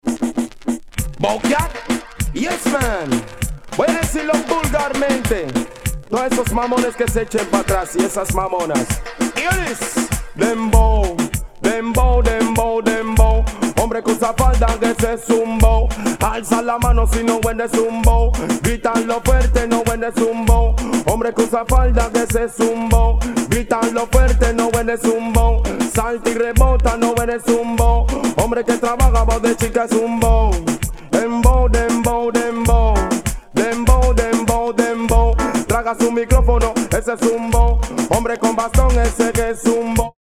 Acapellla Version